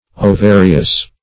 Ovarious \O*va"ri*ous\